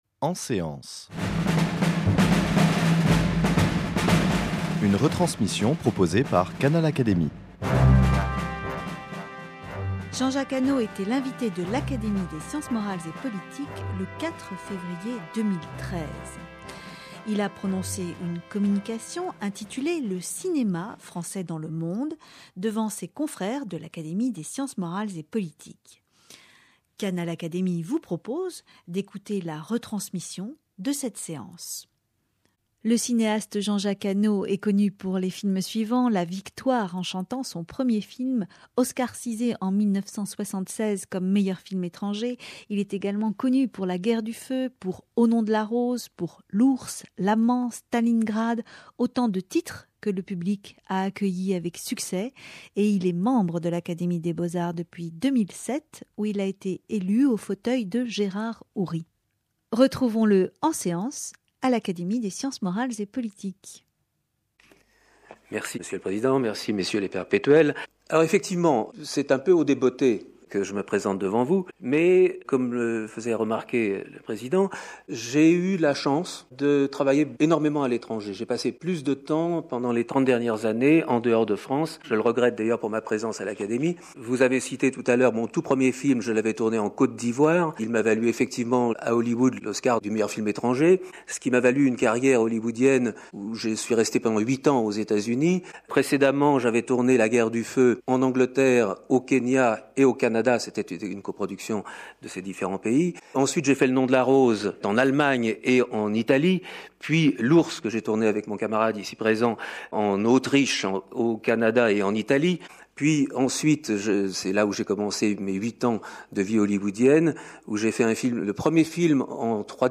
Le point sur la place et le rôle du septième art français : une intervention passionnante suivie des questions de ses confrères et des réponses du cinéaste à brûle-pourpoint.